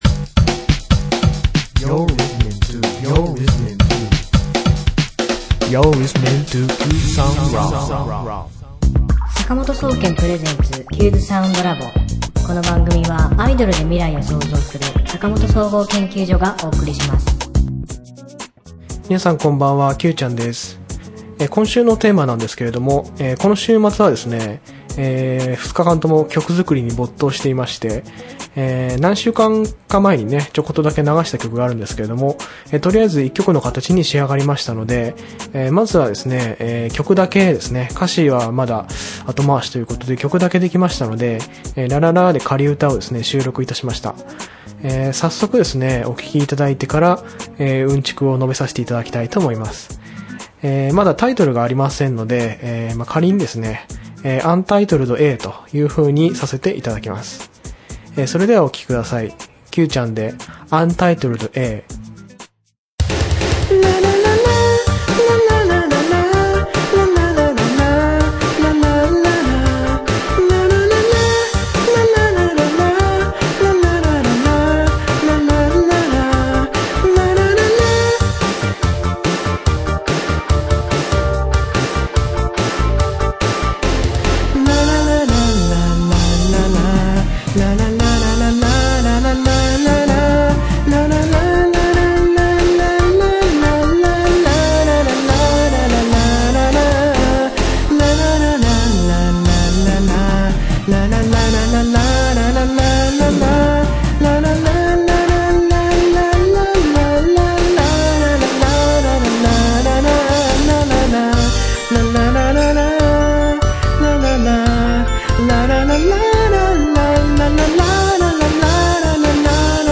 今週のテーマ：新曲ができましたー（仮歌のみ） 今週末は曲作りに没頭していました。